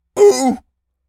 seal_walrus_hurt_04.wav